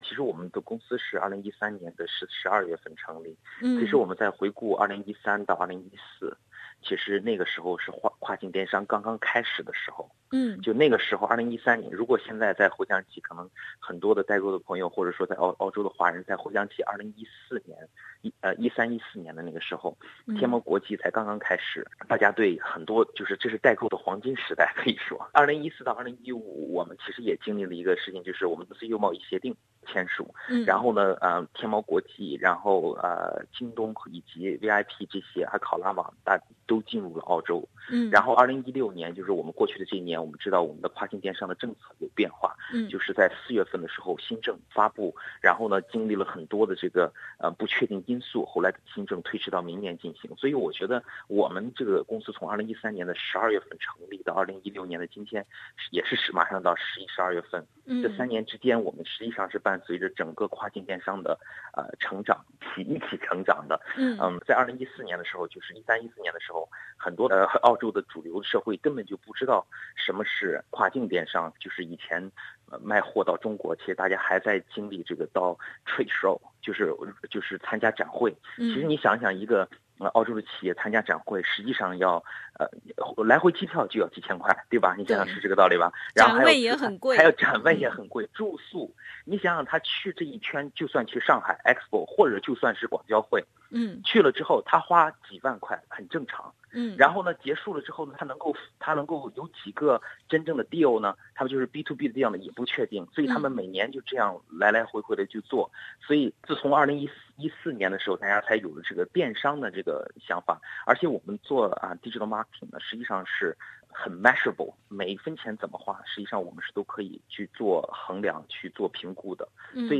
“打了鸡血"的创业公司“Think China"创业访谈